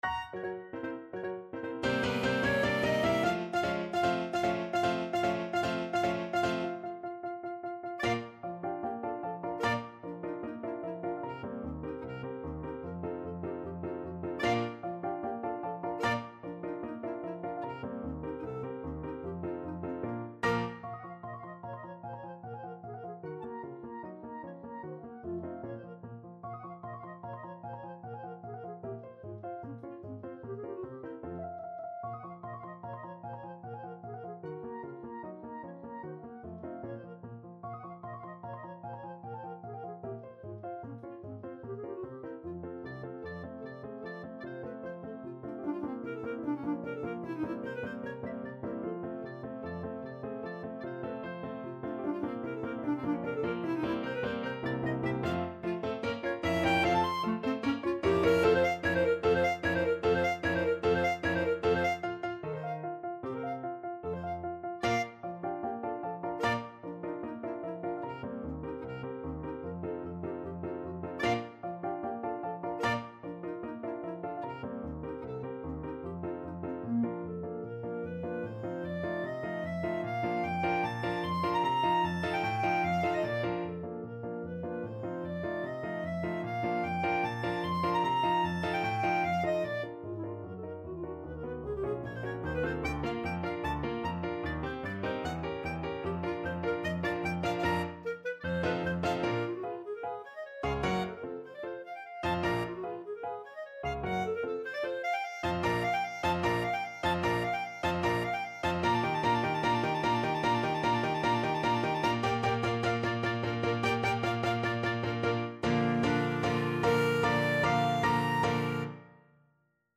Clarinet
2/4 (View more 2/4 Music)
Bb major (Sounding Pitch) C major (Clarinet in Bb) (View more Bb major Music for Clarinet )
Allegro vivacissimo ~ = 150 (View more music marked Allegro)
Classical (View more Classical Clarinet Music)